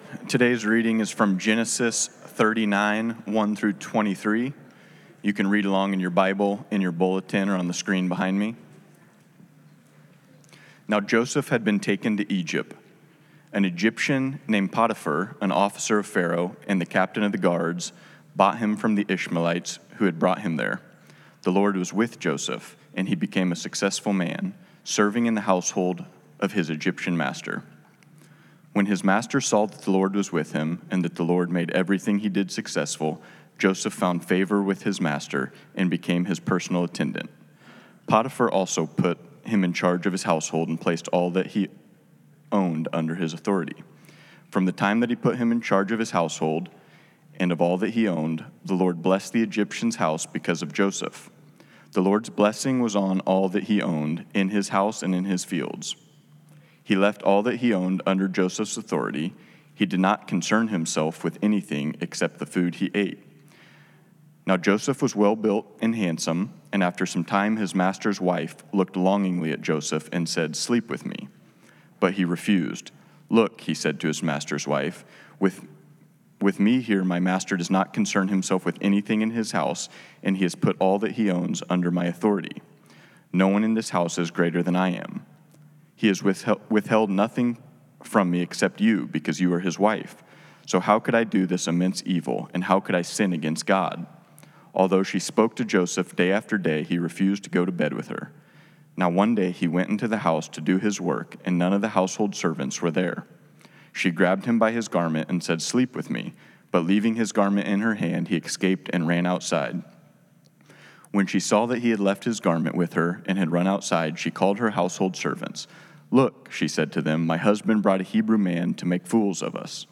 Proof of God's Presence Scripture Text: Genesis 39:1-23 Date: October 19, 2025 AI Generated Summary: In this sermon, we explore Genesis 39, emphasizing how God's presence remains with Joseph even through slavery and false imprisonment. We see three ways in which God's presence is evident: by making us a blessing to others through excellent work, helping us overcome temptation through love for God, and sustaining us through difficult and unfair circumstances.